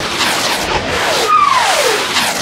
shell_fly.ogg